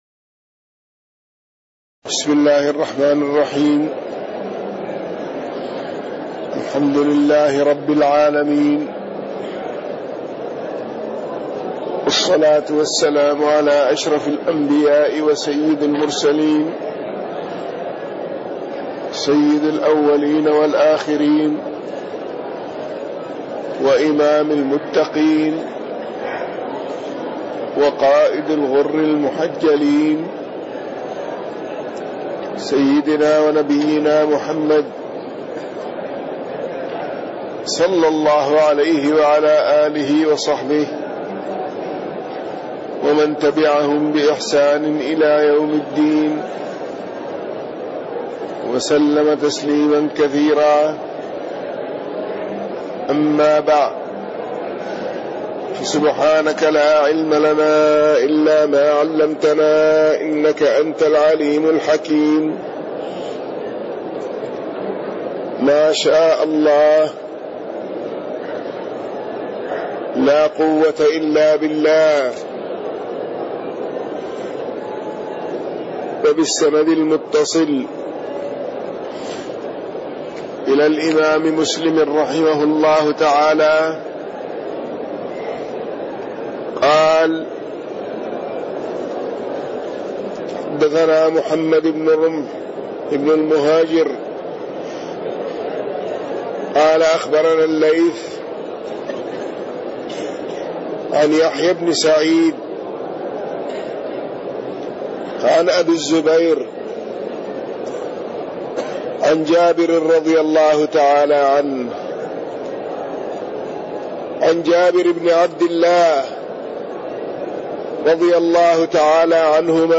تاريخ النشر ٢٦ ذو الحجة ١٤٣٢ هـ المكان: المسجد النبوي الشيخ